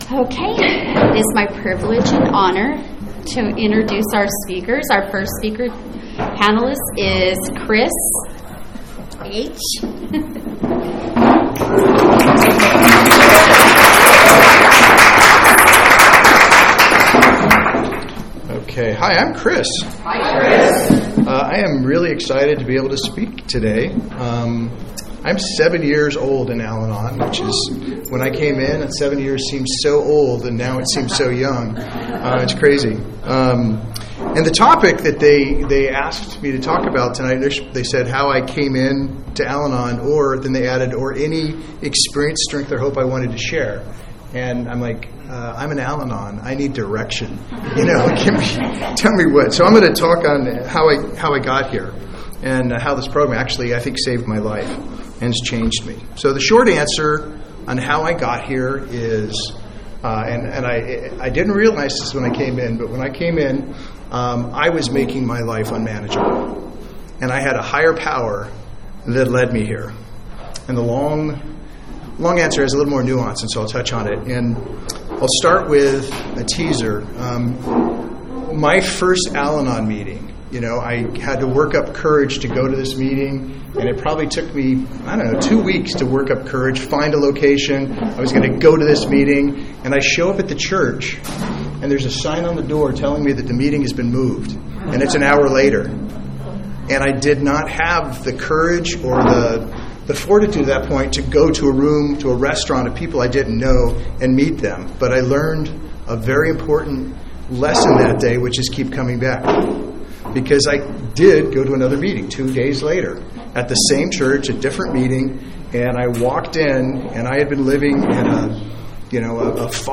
Al-Anon Panel &#8211